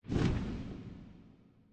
sounds / mob / phantom / flap3.ogg
flap3.ogg